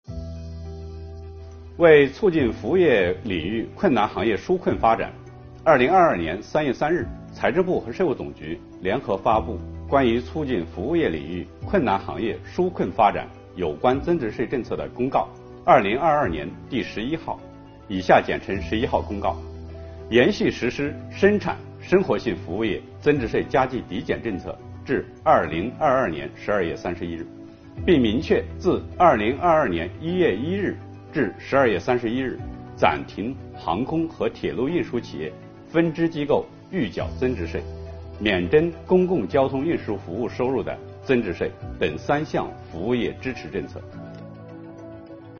本期课程由国家税务总局货物和劳务税司副司长刘运毛担任主讲人，对2022年服务业领域困难行业纾困发展有关增值税政策进行详细讲解，方便广大纳税人更好地理解和享受政策。